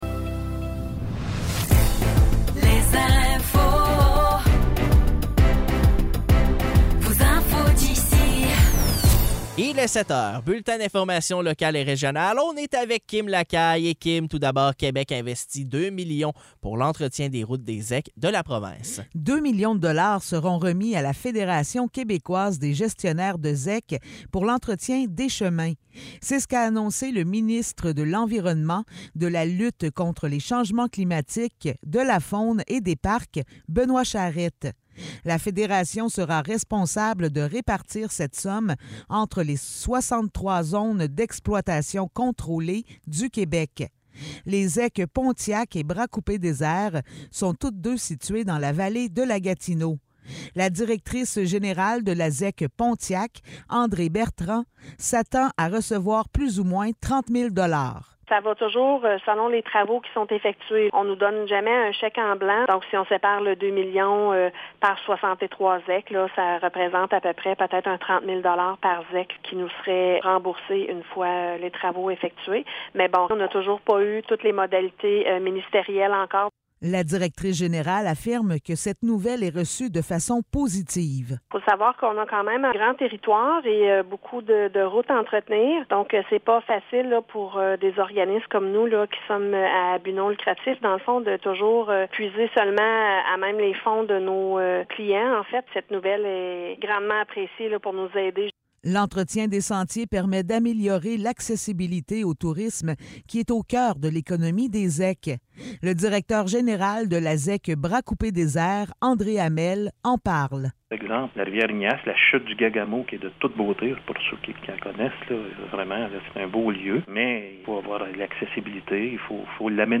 Nouvelles locales - 25 octobre 2023 - 7 h